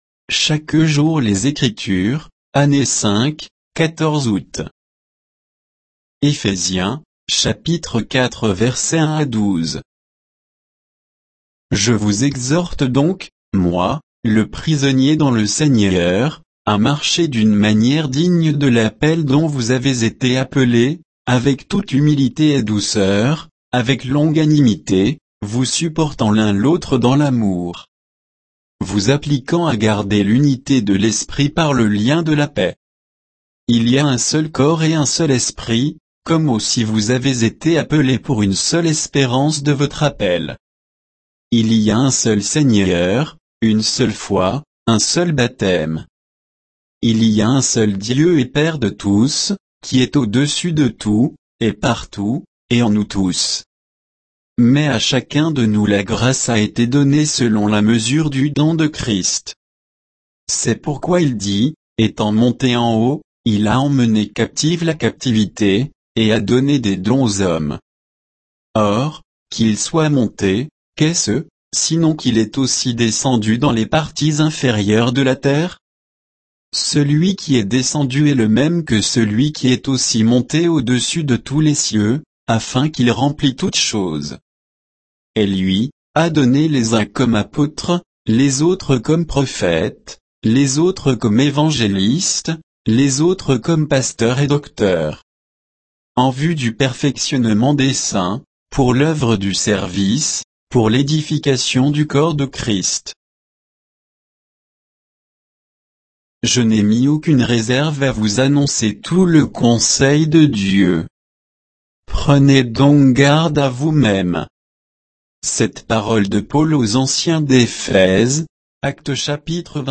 Méditation quoditienne de Chaque jour les Écritures sur Éphésiens 4